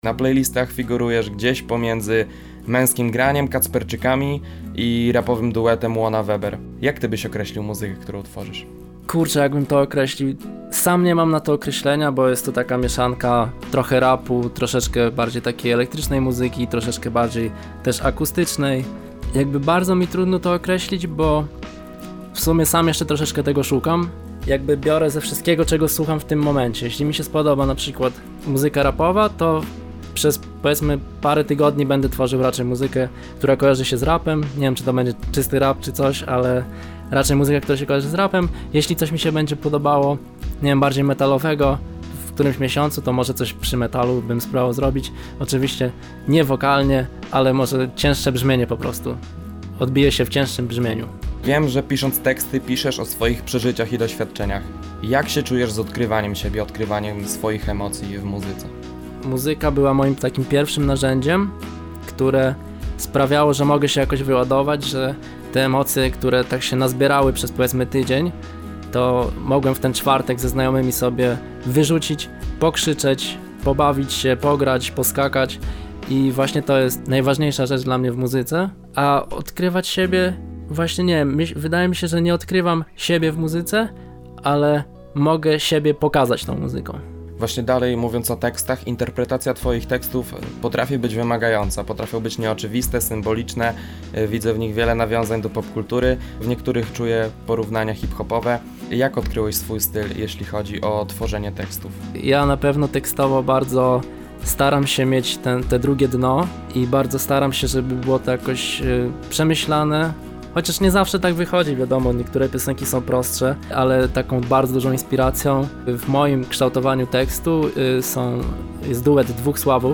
Uważnie słuchajcie ścieżki dźwiękowej – rozmowa przeplatana jest
autorską muzyką oraz niepublikowanymi dotychczas fragmentami piosenek.